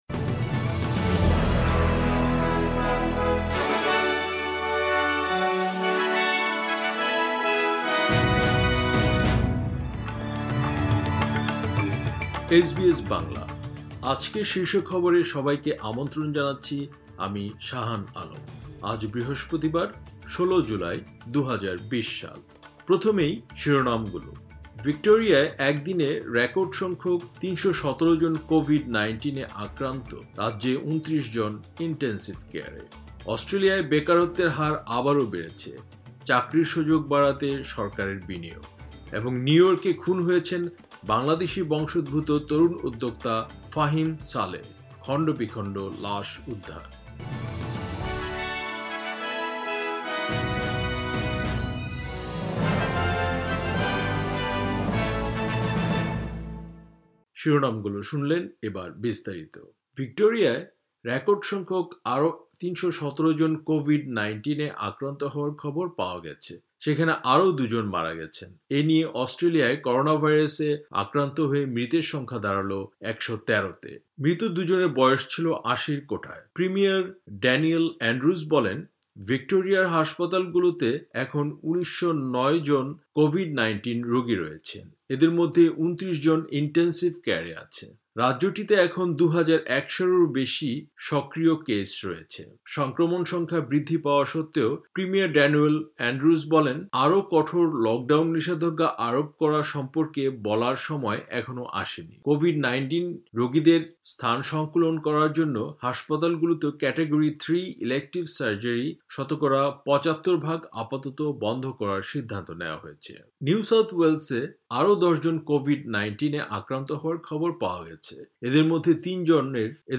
এসবিএস বাংলা শীর্ষ খবর : ১৬ জুলাই ২০২০